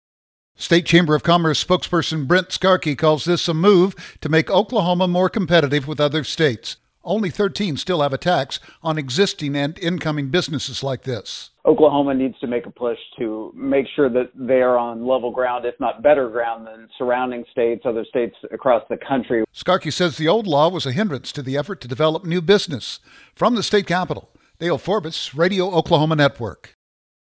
provide details on Oklahoma’s franchise tax.